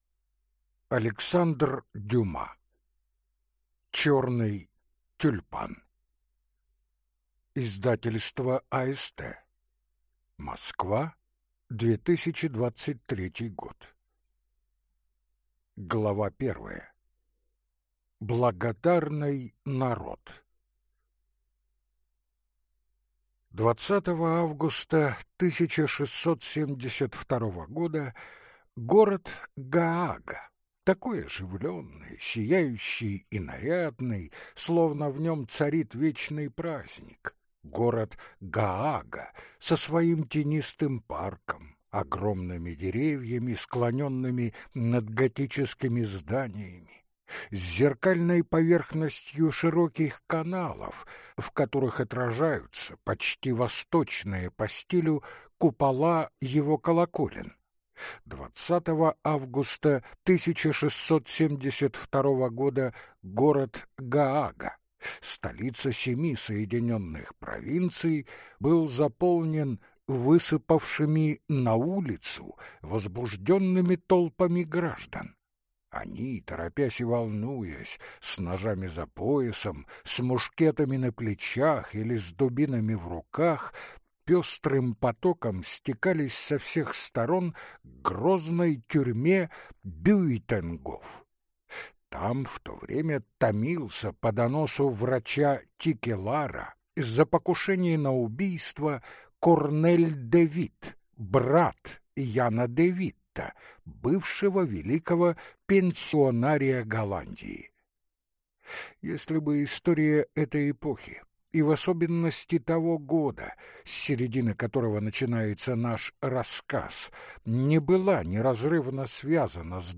Aудиокнига Черный тюльпан